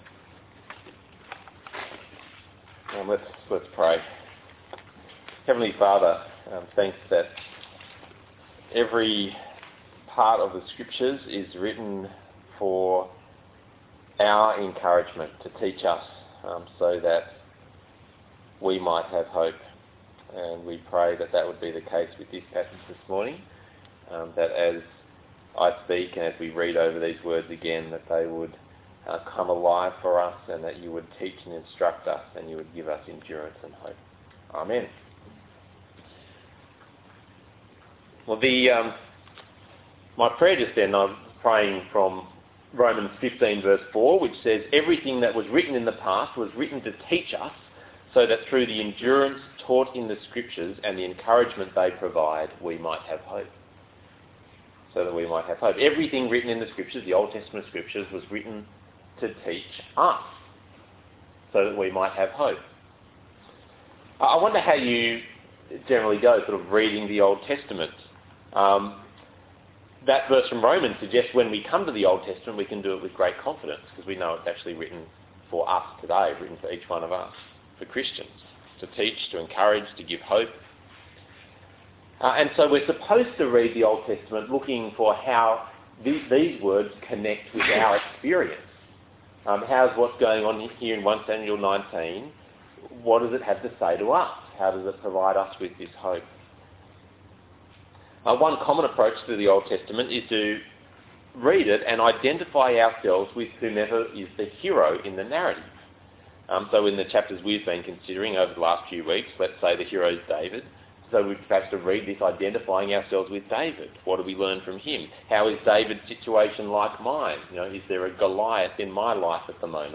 The Kings and I Passage: 1 Samuel 19:1-24 Talk Type: Bible Talk